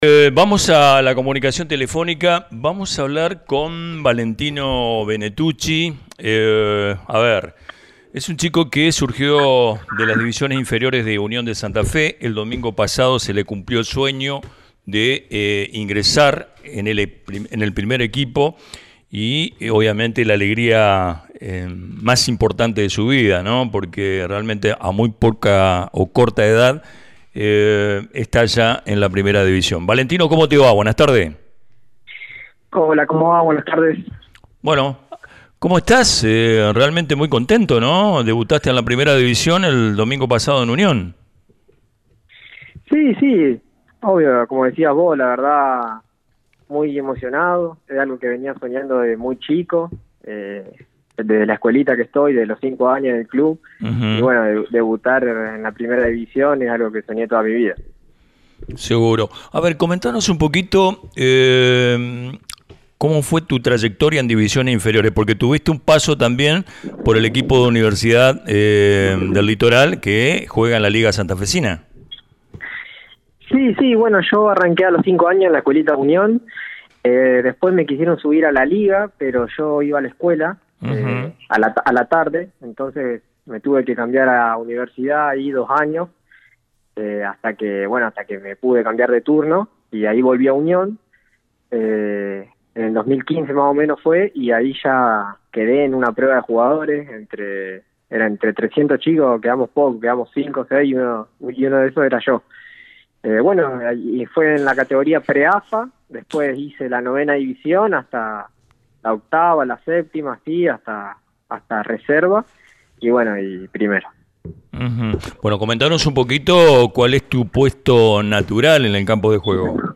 A continuación, presentamos la entrevista completa